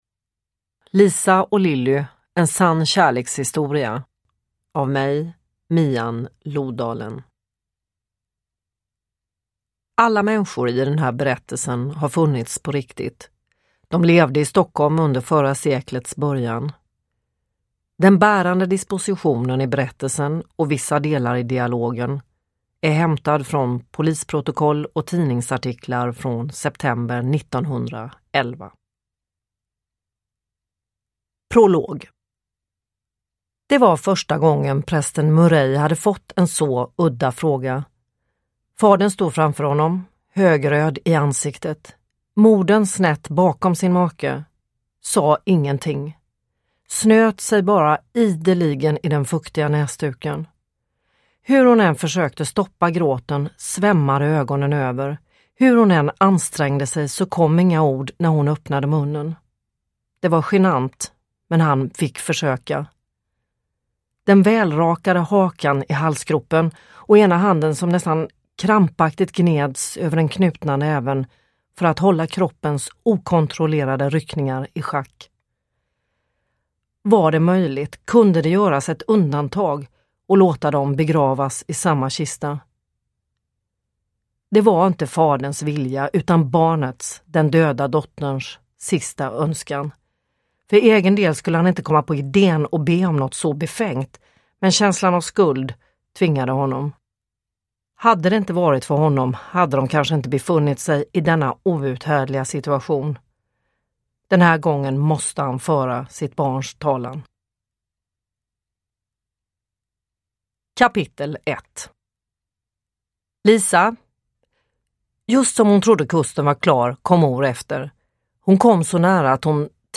Uppläsare: Mian Lodalen
Ljudbok